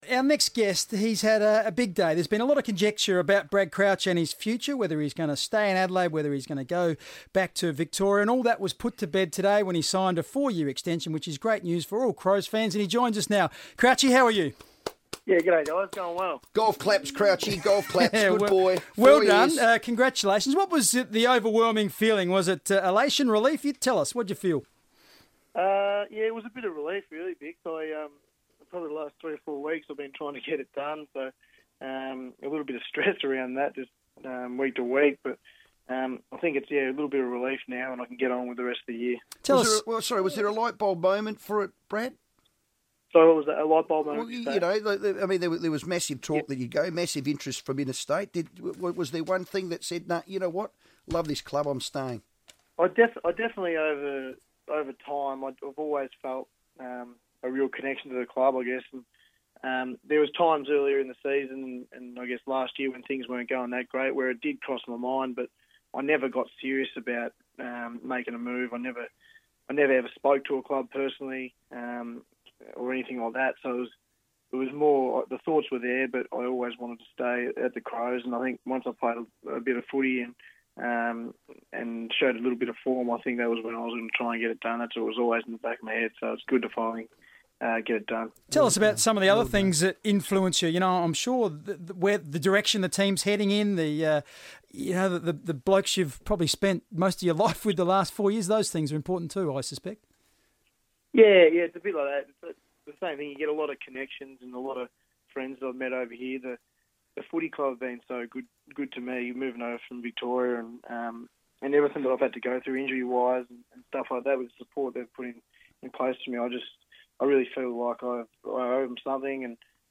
Crows midfielder Brad Crouch talks to FIVEaa fresh off the back of signing a new four year deal that keeps him at West Lakes until at least 2020